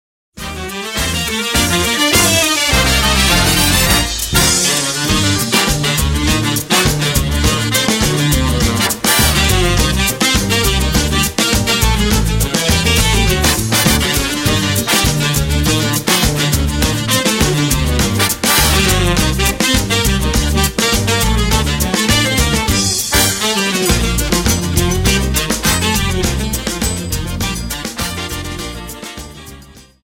Samba 50 Song